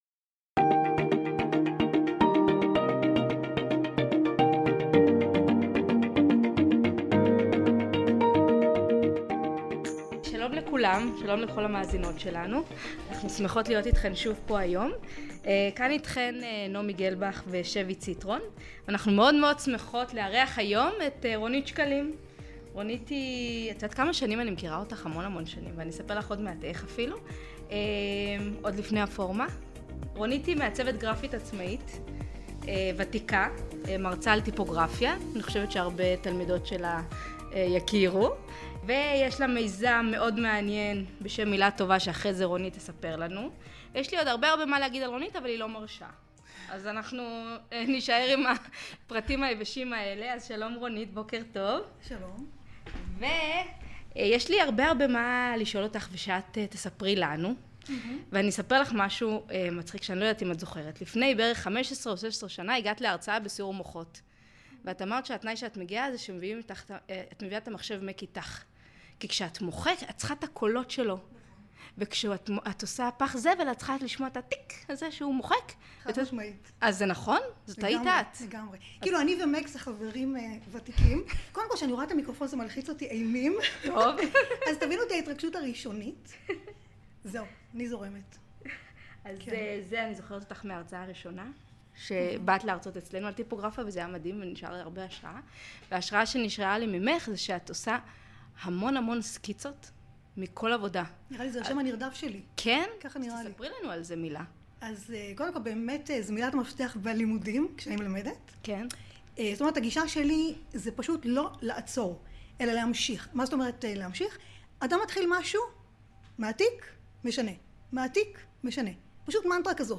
בשיחה צפופה ואנרגטית איתה, ניסינו להבין ביחד את הסיפור של תשוקה וחשק לעיצוב: מהו הדלק שלה, איך מעבירים ומלמדים את התלמידות גם לאהוב ולחשוב עיצוב, איך מלמדים עיצוב דרך הידיים, ולמה זה טוב כשיש הפסקת חשמל באמצע שיעור עיצוב.